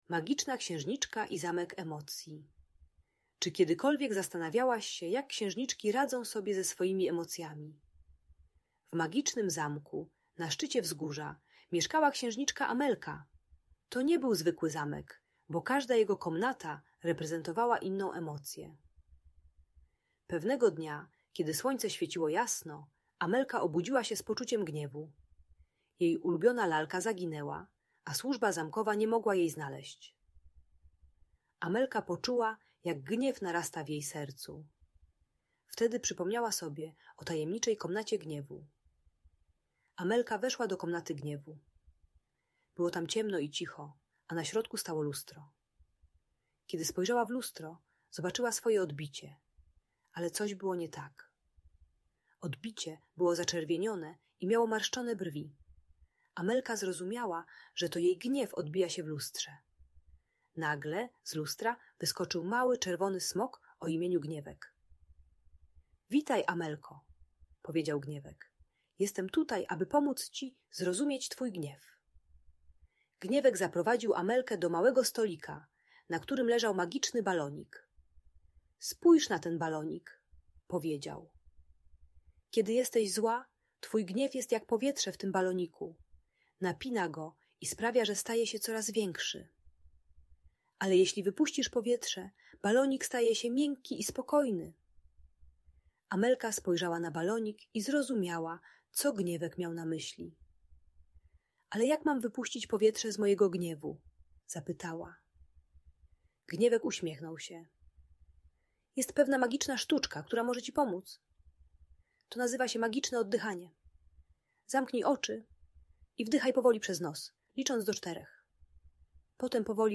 Magiczna Księżniczka i Zamek Emocji - Audiobajka